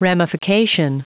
Prononciation du mot ramification en anglais (fichier audio)
Prononciation du mot : ramification